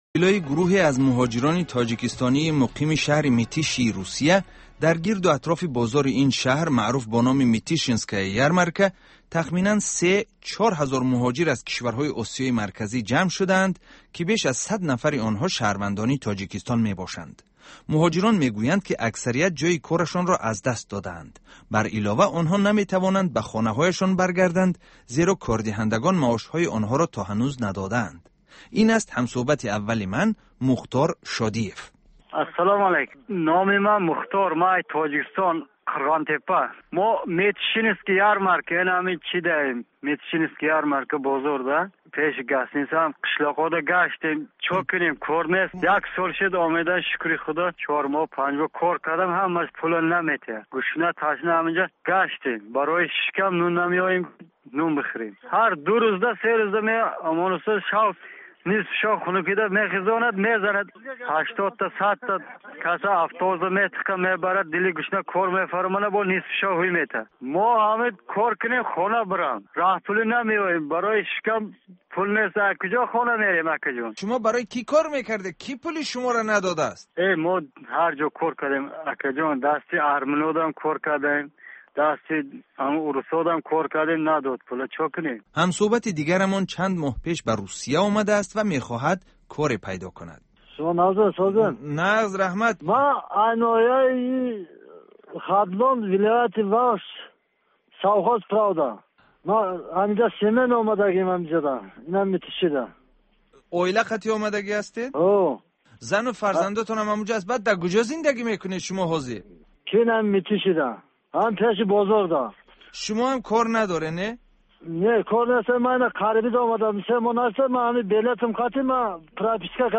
Бо пахши ишораи ин аудио сӯҳбати муҳоҷирони тоҷик, ки дар Митиши бо мушкил мувоҷеҳ шудаанд